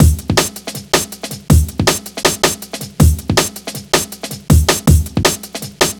Index of /90_sSampleCDs/Zero-G - Total Drum Bass/Drumloops - 1/track 08 (160bpm)